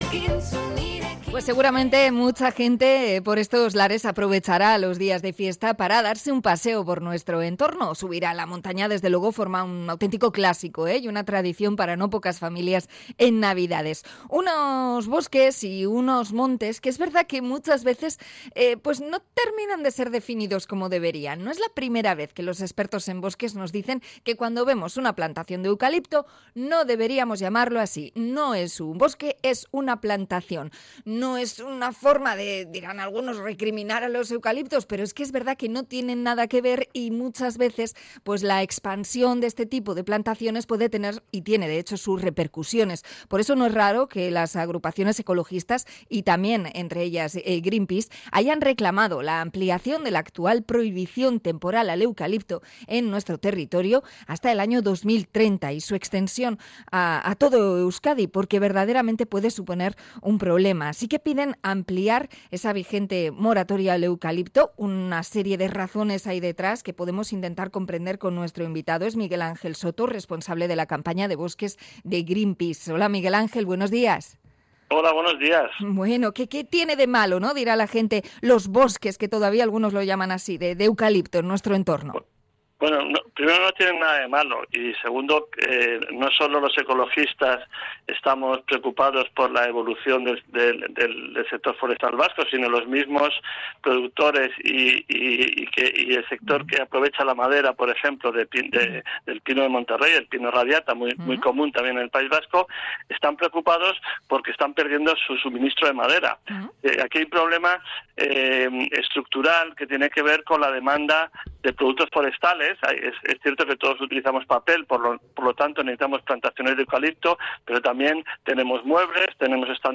Entrevista a Greenpeace por la moratoria a las plantaciones de eucaliptos